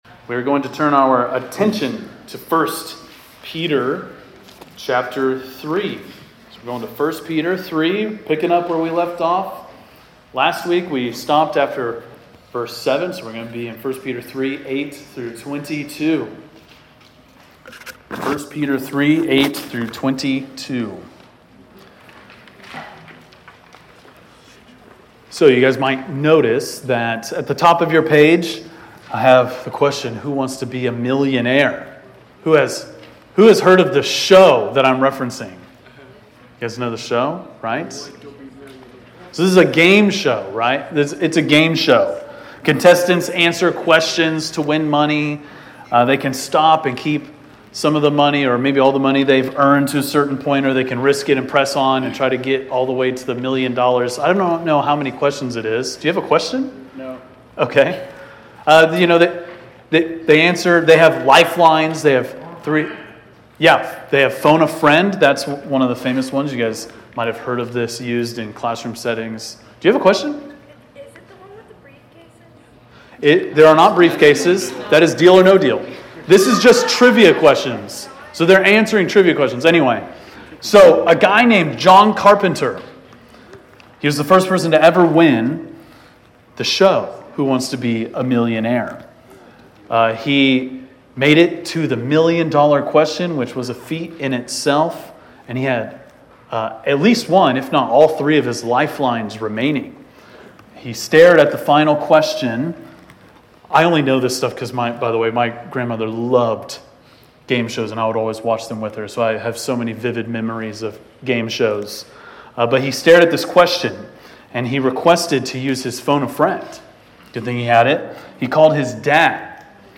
preaches through 1 Peter 3:8-22.